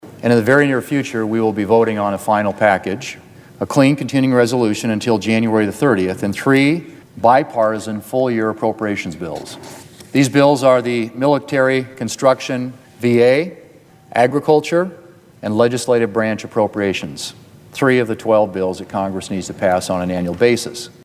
REPUBLICAN MAJORITY LEADER JOHN THUNE OF SOUTH DAKOTA SPOKE ON THE SENATE FLOOR MONDAY MORNING: